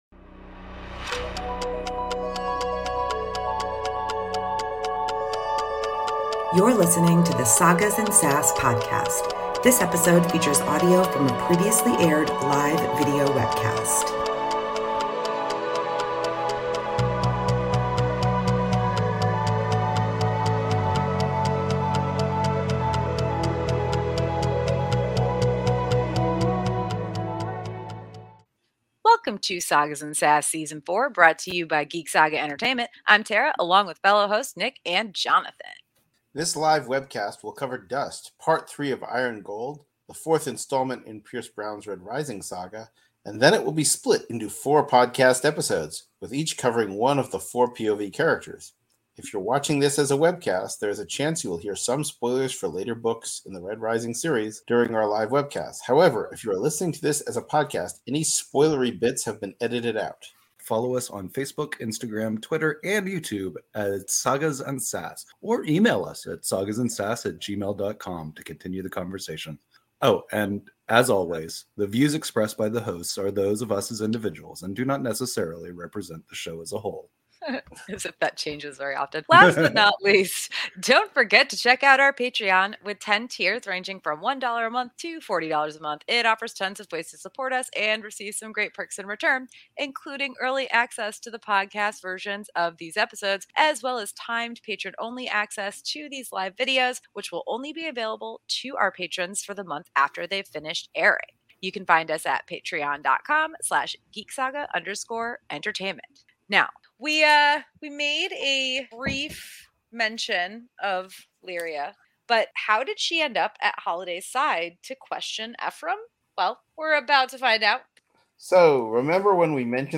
Episode 65 of the Sagas & Sass Podcast originally aired as a live webcast on April 5, 2023.